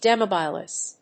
音節de･mo･bi･lise発音記号・読み方dìːmóʊbəlàɪz